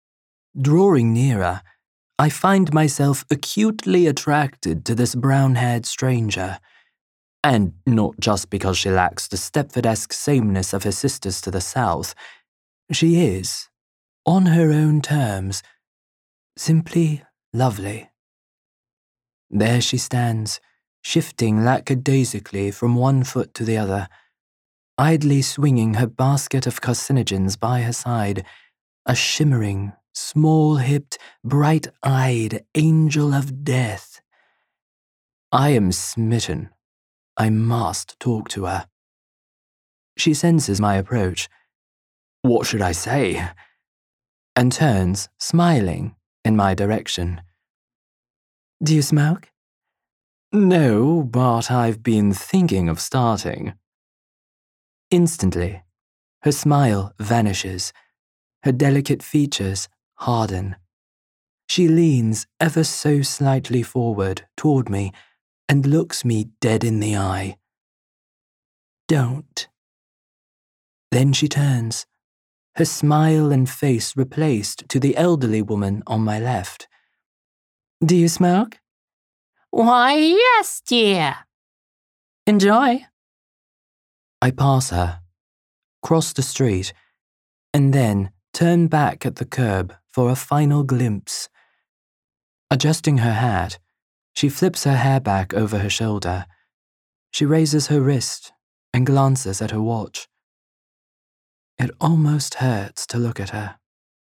“My Non-Binary Trans Voice